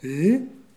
Les sons ont été découpés en morceaux exploitables. 2017-04-10 17:58:57 +02:00 133 KiB Raw History
he-etonnement_01.wav